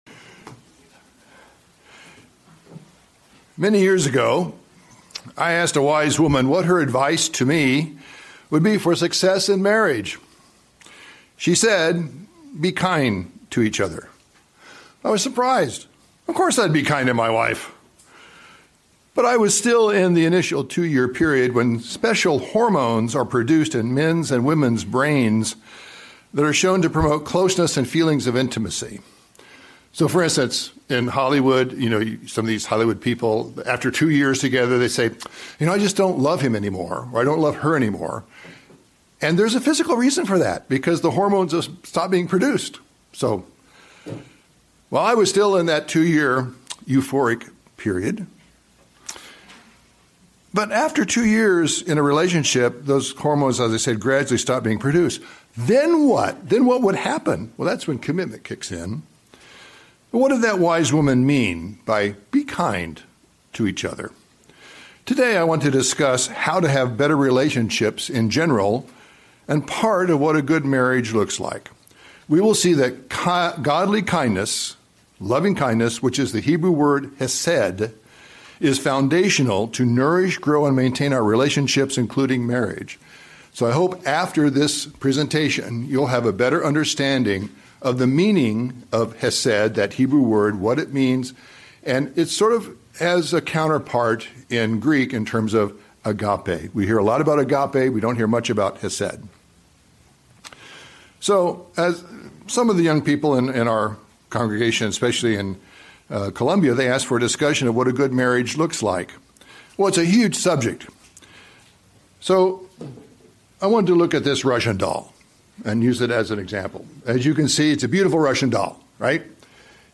Sermons
Given in Columbia, MD